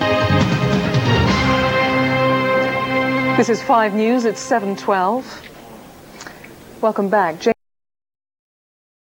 The original 5 news, presented from the newsroom at ITN.
After the break, Kirsty welcomes us back.